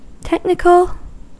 Ääntäminen
IPA : /ˈtɛk.nɪk.əl/